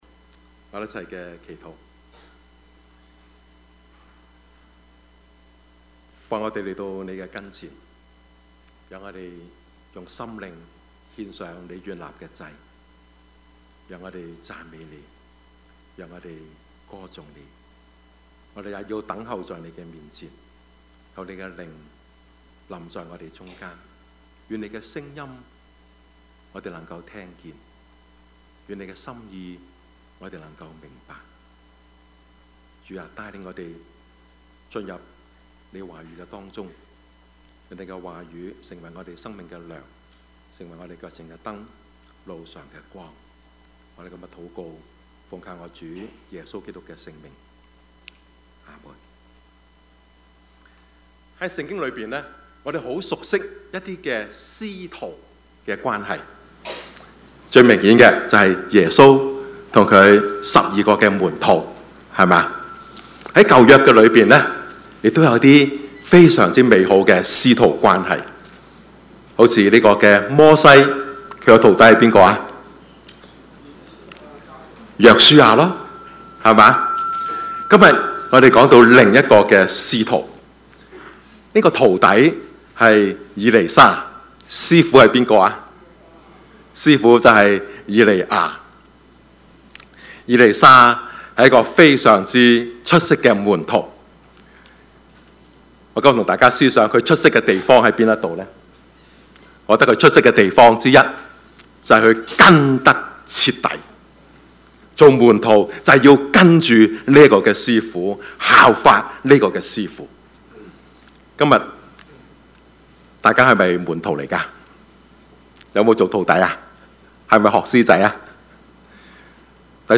Service Type: 主日崇拜
Topics: 主日證道 « 金齡歲月 每週一字之「父」一 »